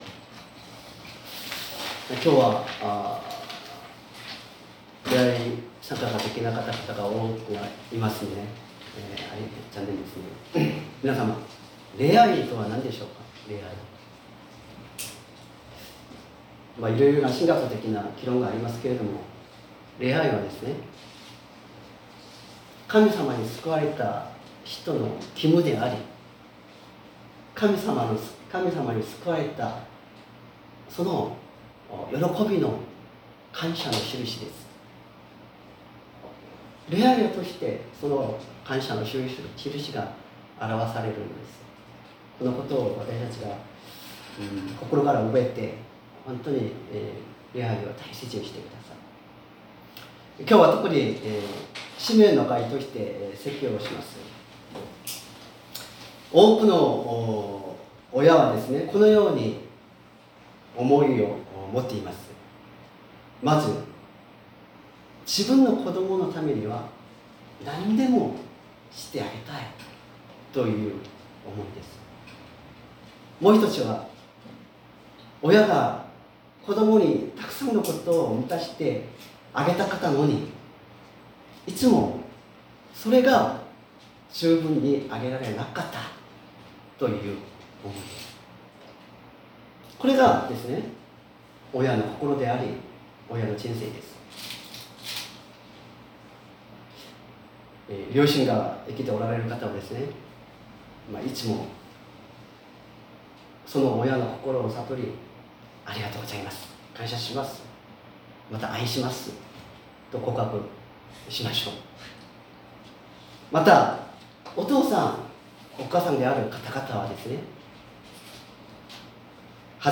説教アーカイブ 2024年09月15日朝の礼拝「私がお前に変わって」
音声ファイル 礼拝説教を録音した音声ファイルを公開しています。